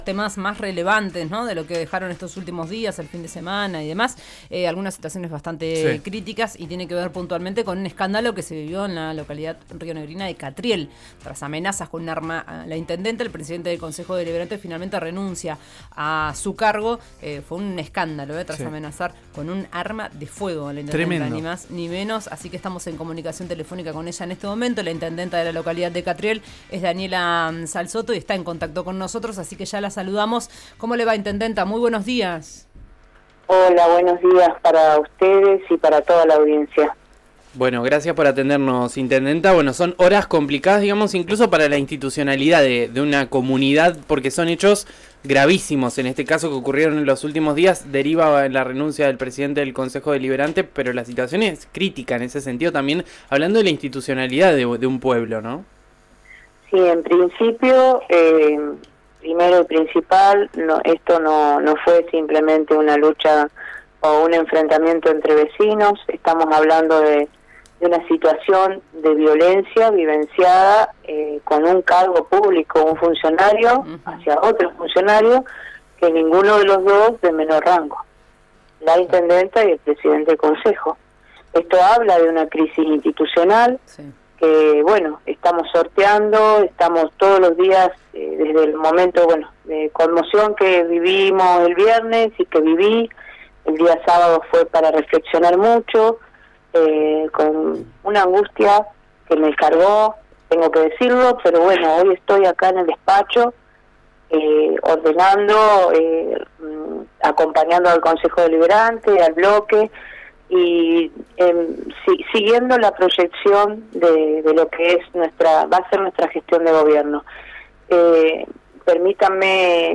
La intendenta de Catriel habló con RÍO NEGRO RADIO y relató lo ocurrido. Dijo que transita una 'crisis institucional' que se debe sortear y mencionó su estado personal.
Escuchá a Daniela Salzotto, intendenta de Catriel en RÍO NEGRO RADIO: